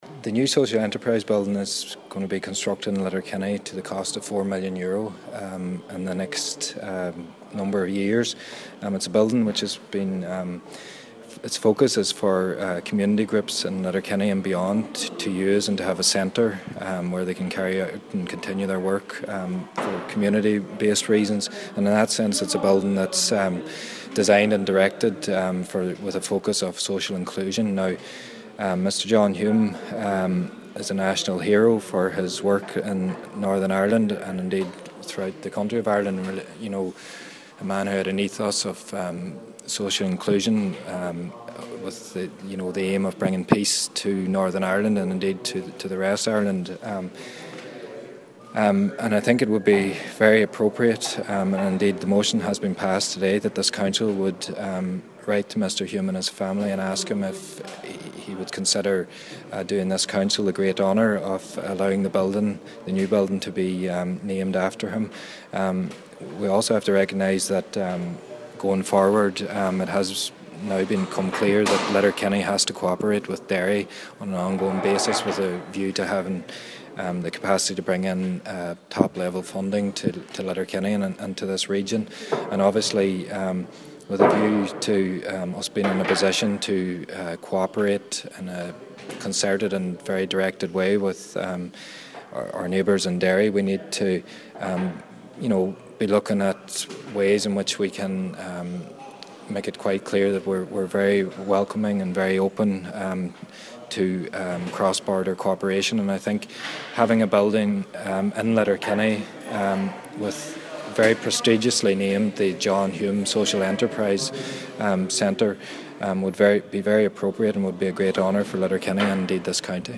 Cllr. Dessie Shiels says there are a number of reasons why it would be the right move: